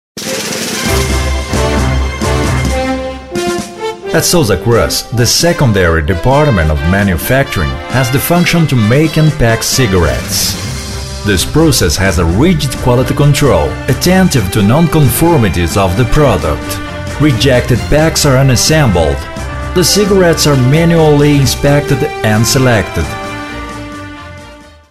Locução em inglês para vídeo da Souza Cruz.